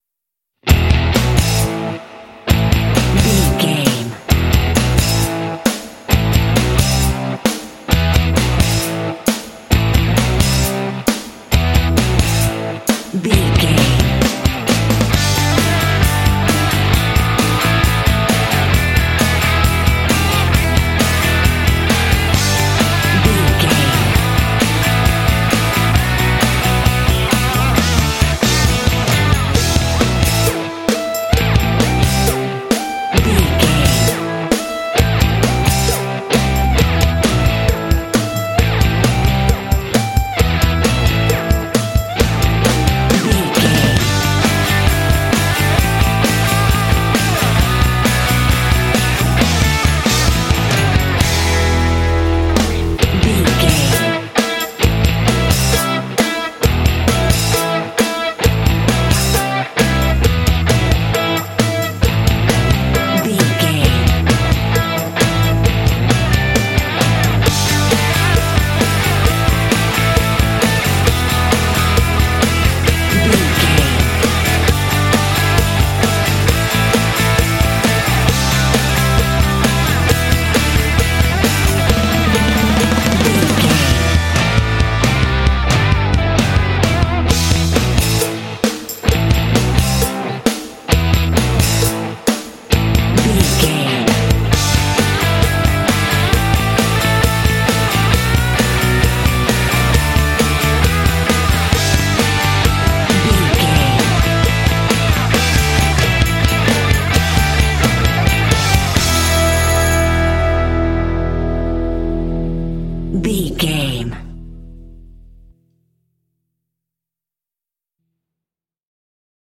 Aeolian/Minor
powerful
energetic
heavy
electric guitar
drums
bass guitar
percussion
rock
heavy metal
classic rock